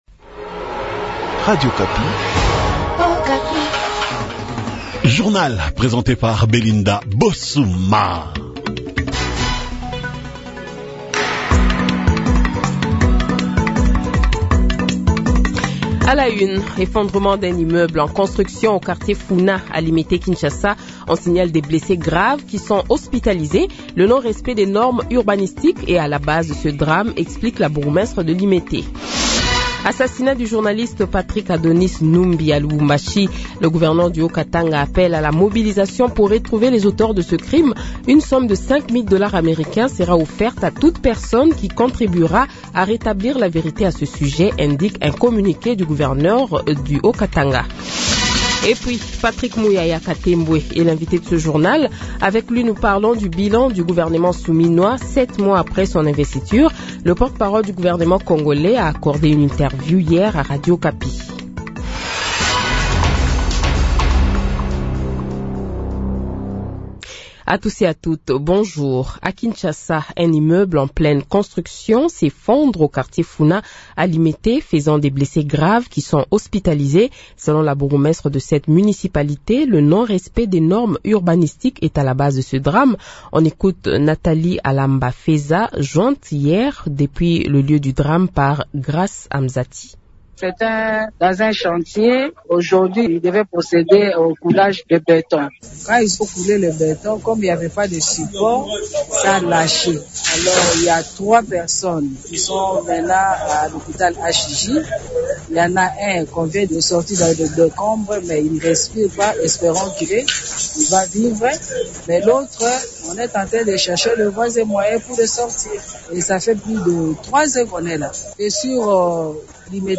Le Journal de 8h, 14 Janvier 2025 :